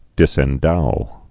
(dĭsĕn-dou)